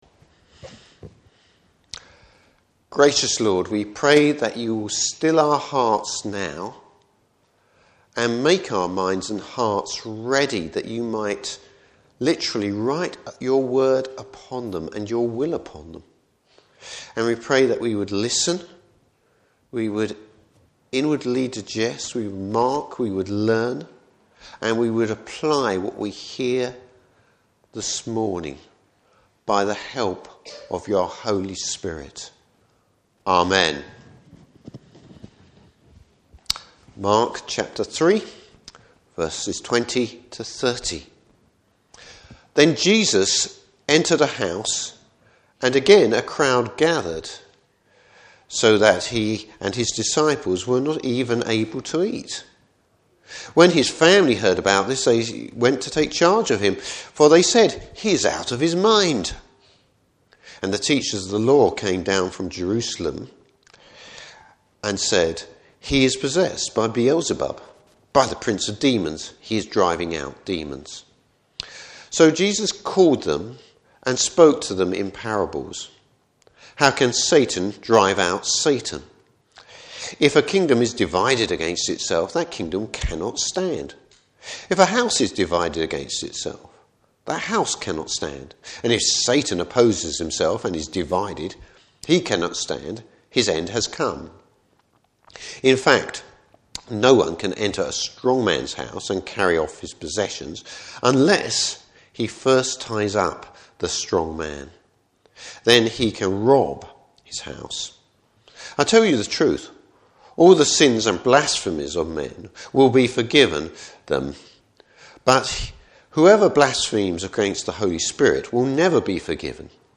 Service Type: Morning Service Jesus corrects a perverted statement and gives a solemn warning!